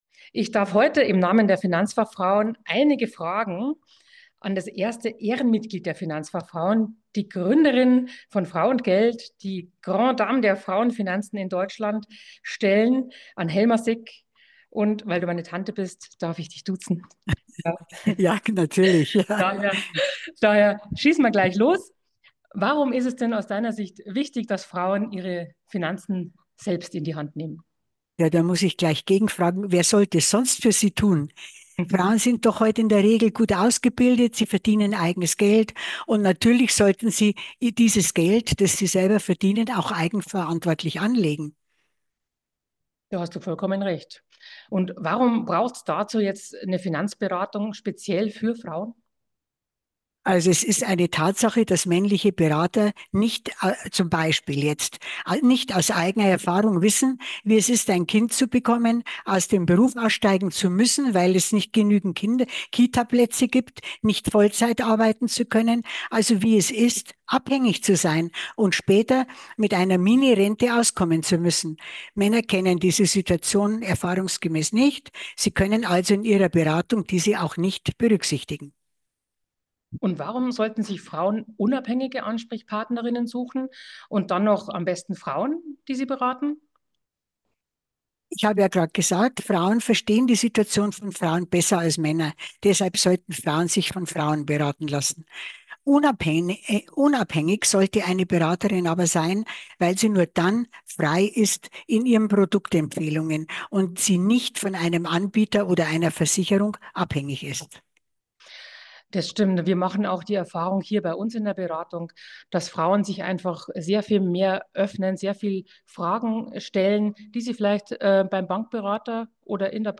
spricht im Interview mit den FinanzFachFrauen offen darüber